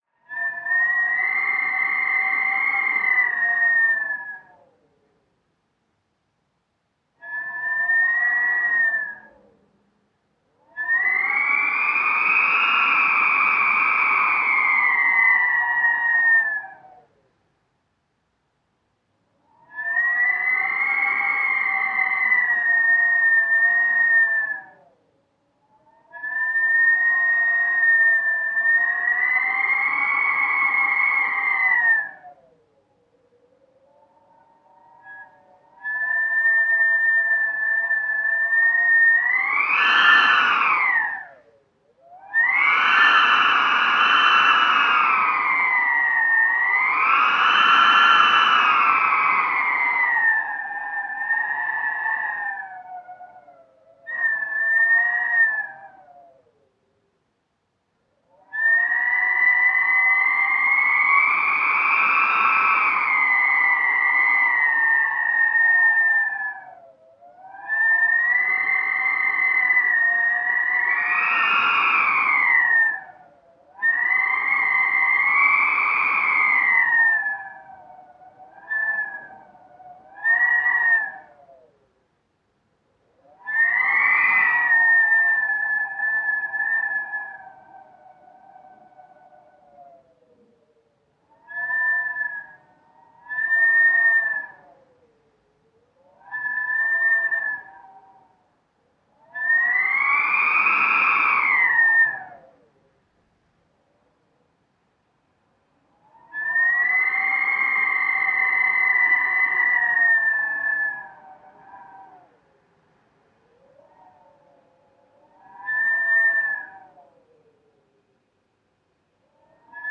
Звуки сквозняка
Продолжительный свист сквозняка в доме